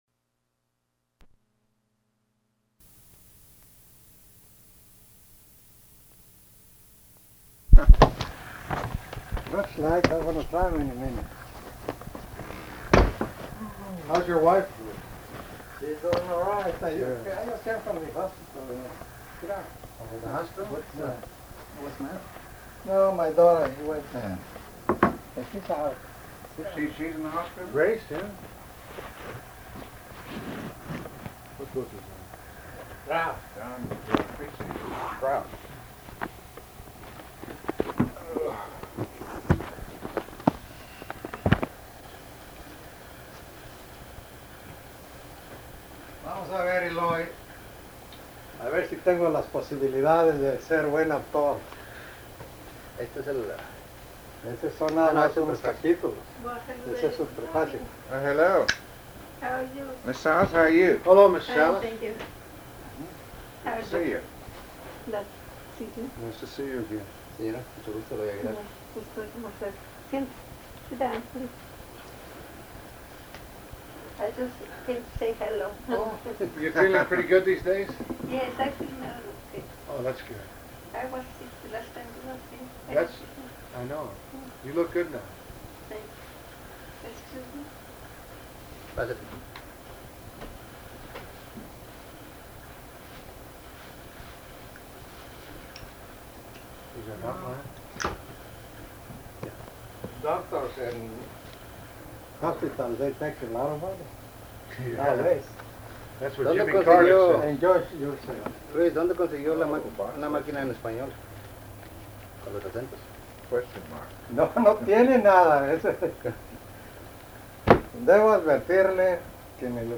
Format Audio tape
Specific Item Type Interview Subject Congressional Elections Texas